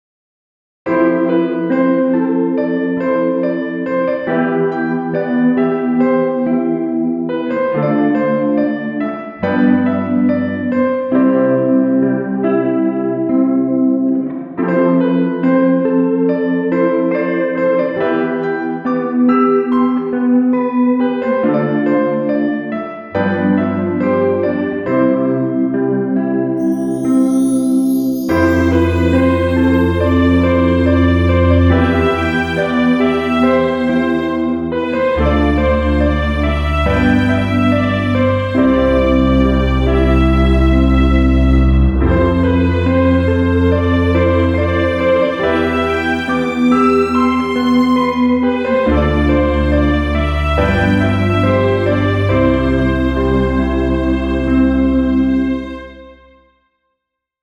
イージーリスニング
ピアノ
ギター
バイオリン
感動